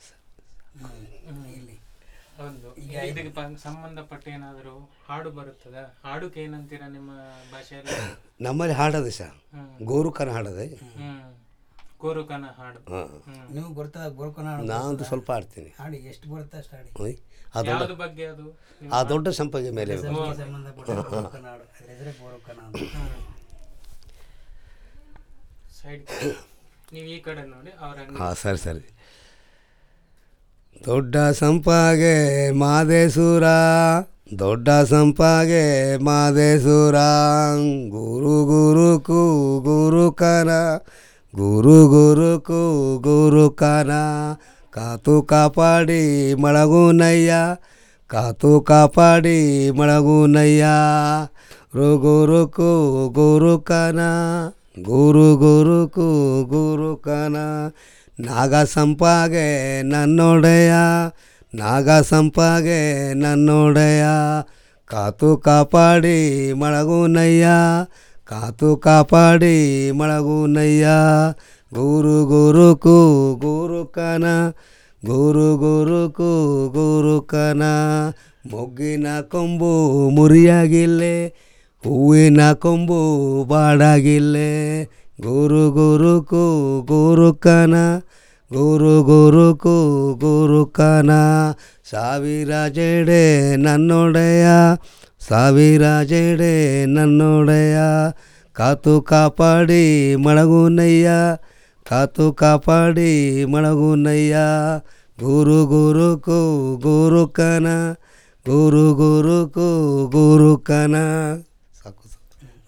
NotesThis is an description about Gorukana song and Gorukana dance: Gorukana means the sound from the evergreen forest, Goruu means sound from the forest and Kana means evergreen forest and another meaning Gurukana means spider, so the Soligas observed the nature, animals, trees, birds, rain , agriculture, god, goddess, flowers, girls, clans, all the songs narrate the about the wildlife, trees, flowers etcs so the the elder people and younger people sing and do the Gorukana dance that time children will learn the songs and dance, each time they sing different songs and dance steps based on the songs.